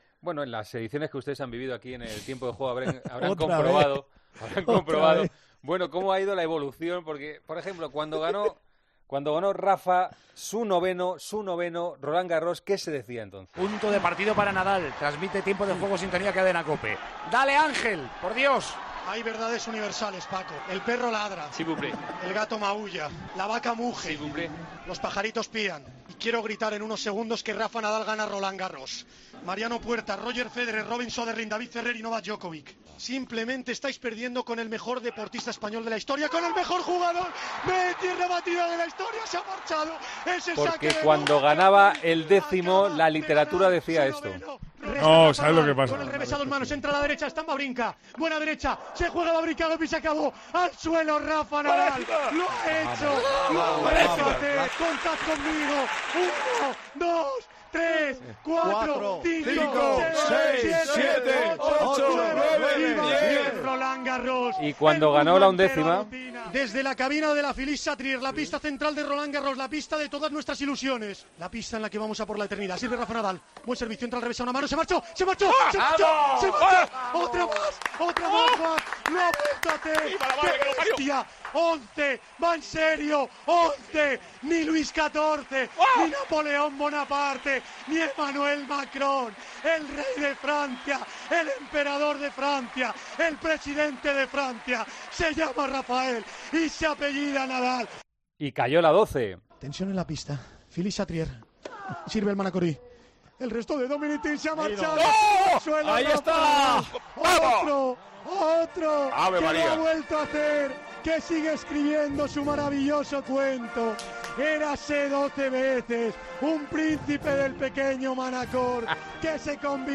Horas antes de una nueva final, en COPE repasamos cómo han sido estas narraciones.